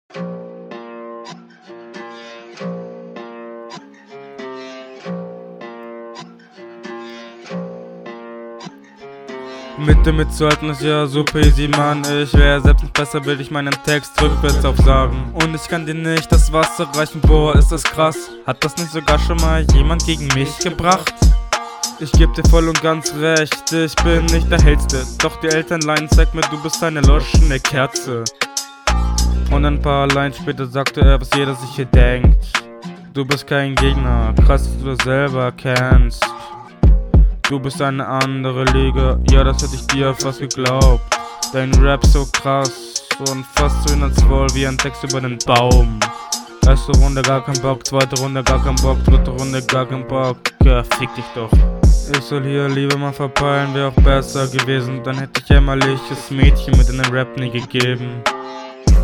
Mische ist hier deutlich besser als in Deinen anderen Runden.
Hier eine Abmische ohne Hall und Echo.
Joa, die runde ist again sehr genuschelt und langweilig gerappt.